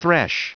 Prononciation du mot thresh en anglais (fichier audio)
Prononciation du mot : thresh